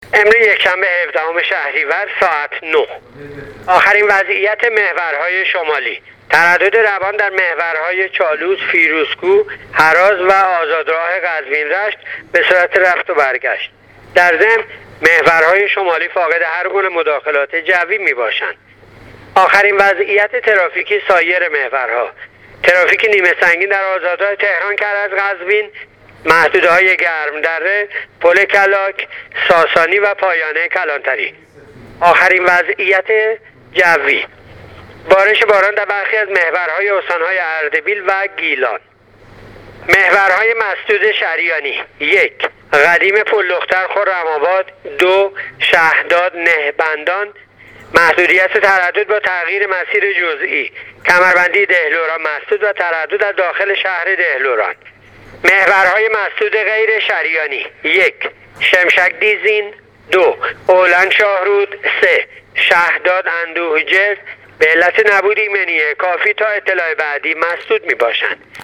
گزارش آخرین وضعیت ترافیکی و جوی جاده‌های کشور را از رادیو اینترنتی پایگاه خبری وزارت راه و شهرسازی بشنوید.